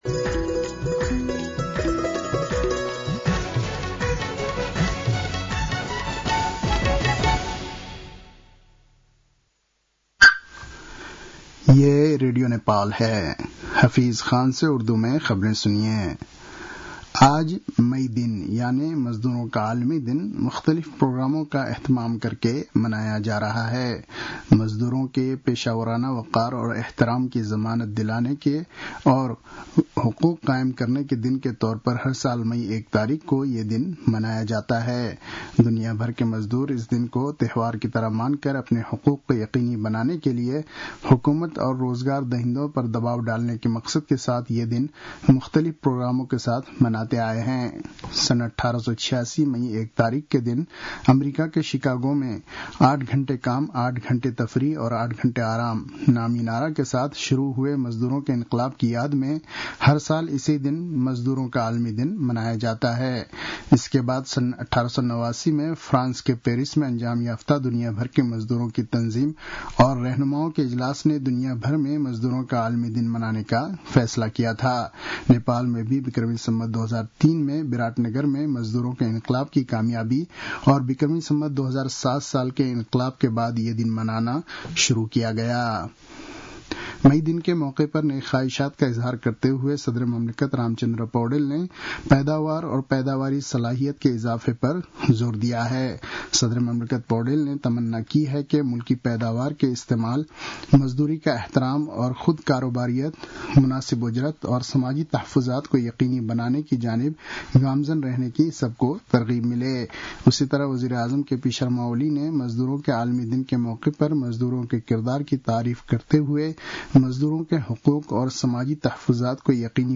उर्दु भाषामा समाचार : १८ वैशाख , २०८२